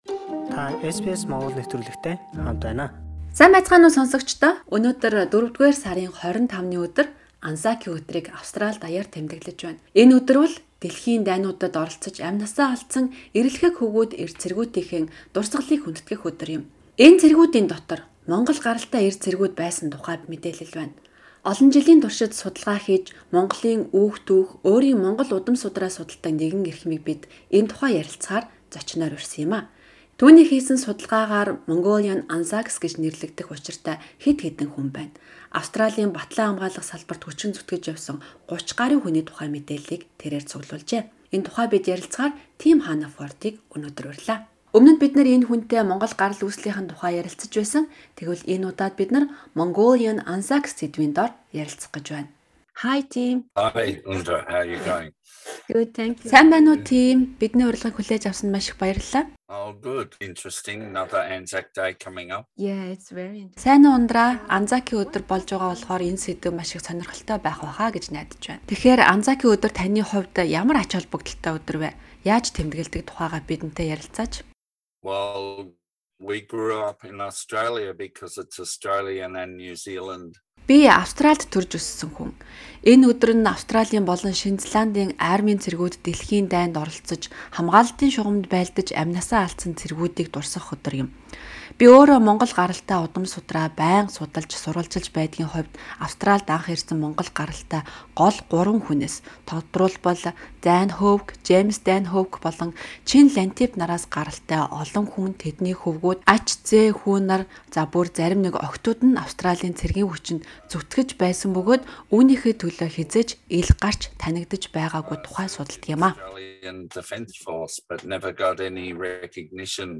Энэ дайнд зөвхөн Австралийн цэргүүдээс гадна Австралид амьдардаг өөр олон улсын цэрэг дайчид татагдан орсон бөгөөд тэдний дотор Монгол гаралтай хүмүүс ч байсан тухай судалгааг манай зочин